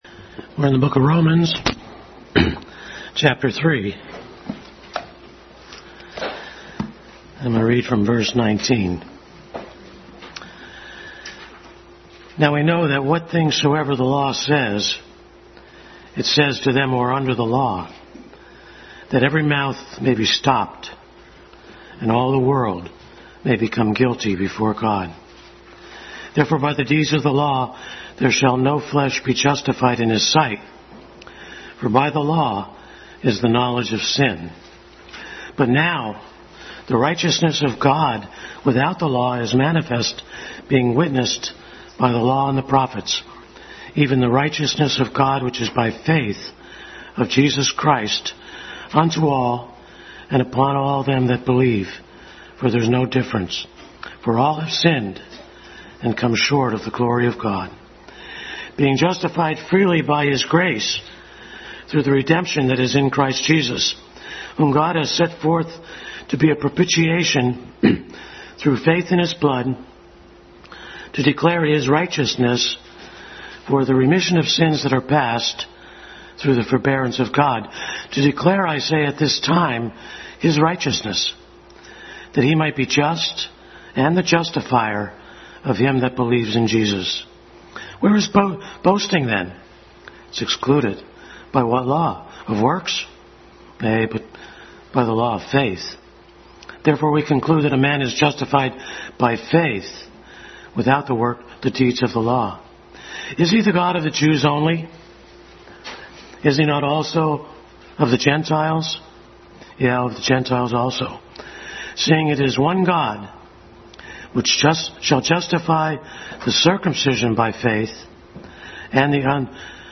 Adult Sunday School continued study in Romans.
Romans 3:19-31 Service Type: Sunday School Adult Sunday School continued study in Romans.